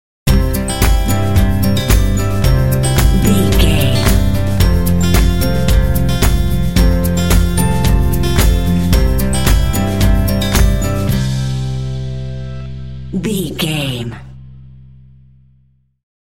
Uplifting
Ionian/Major
cheerful/happy
joyful
acoustic guitar
bass guitar
drums
percussion
electric piano
indie
pop
contemporary underscore